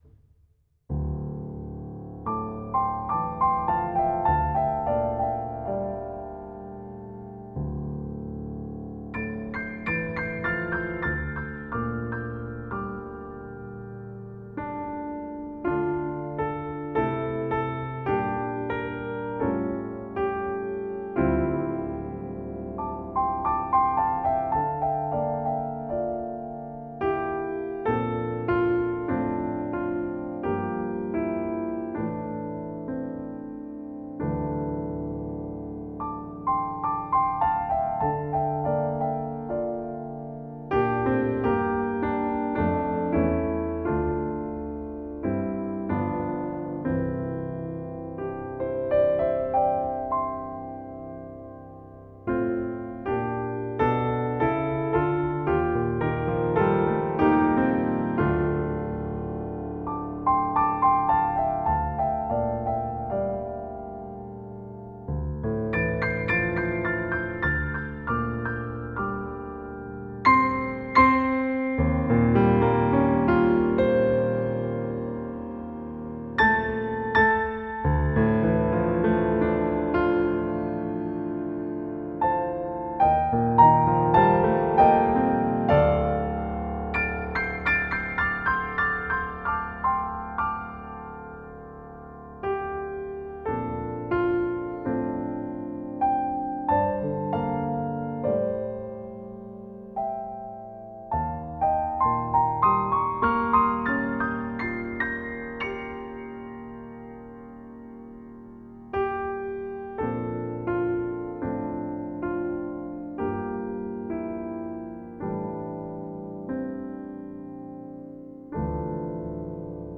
Level: Late Intermediate